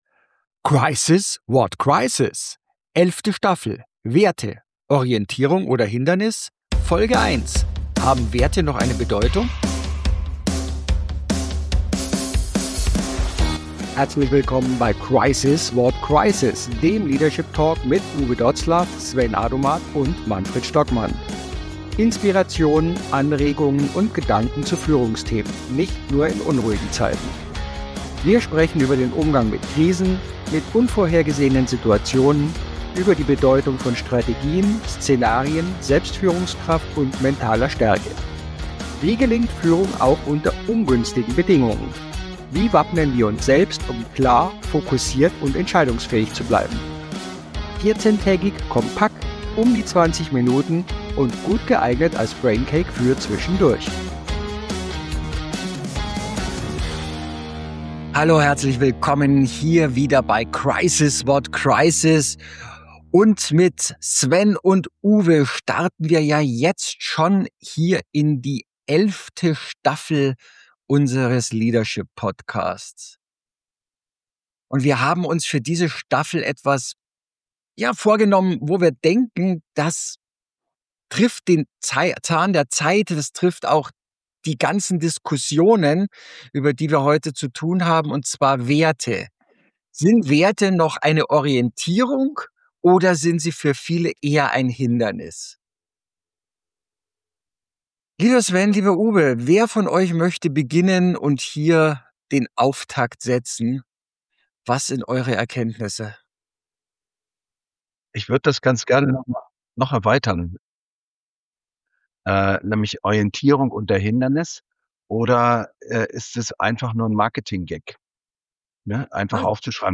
Wann werden Werte zur Belastung, wann zur Kraftquelle? Die drei diskutieren leidenschaftlich über Leistungsorientierung, Gemeinwohl, Marktlogik, Diversity und den schmalen Grat zwischen hilfreicher Vereinfachung und gefährlicher Komplexitätsreduktion.